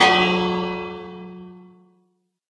minecraft_bell.wav